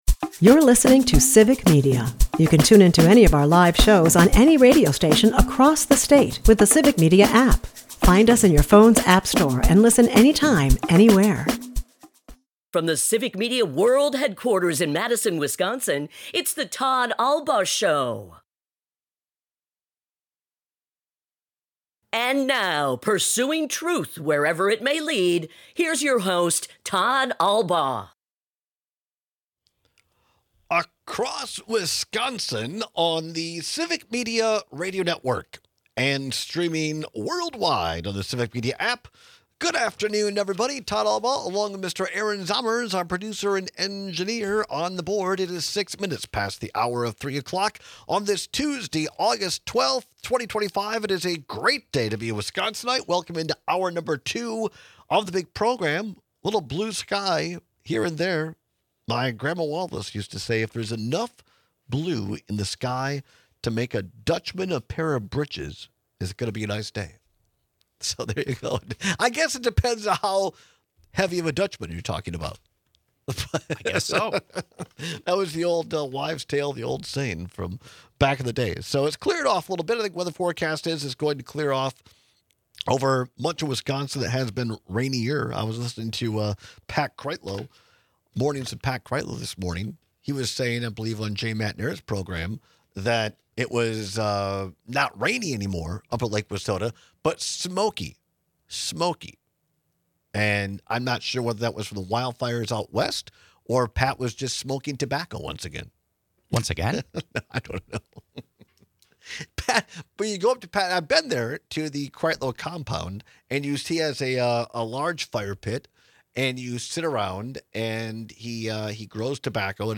At the bottom of the hour, State Senator Kelda Roys joins us in-studio to share some excellent news. Senator Roys was the first Democrat to support a bill that opens up nurses to run their own medical practices in our state as Advanced Practice Registered Nurses, or APRNs.